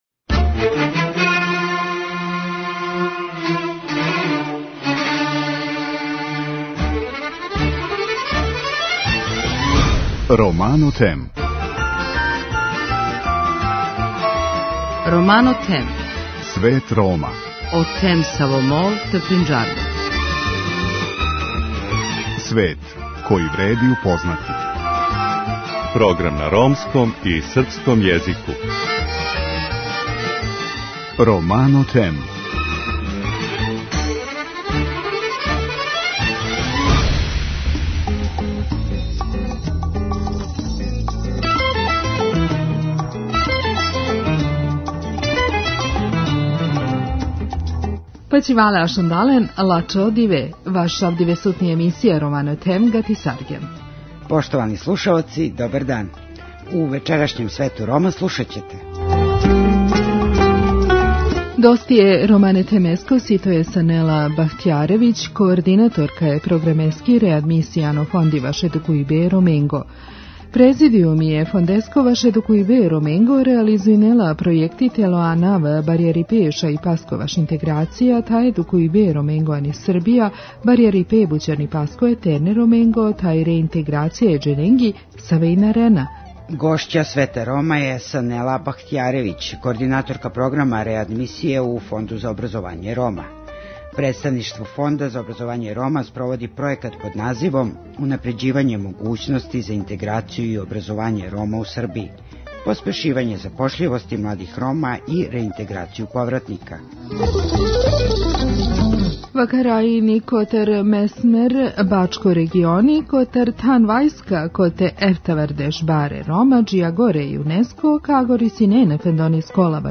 У вечерашњој репортажи говоримо о ромској династији у Шкотској и о титули «Краљ Рома» која је у последњих пет векова припадала различитим личностима.